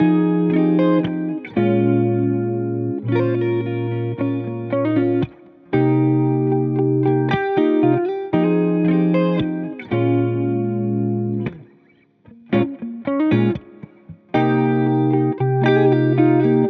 • 18 authentic guitar loops (with Stems: 90 total samples)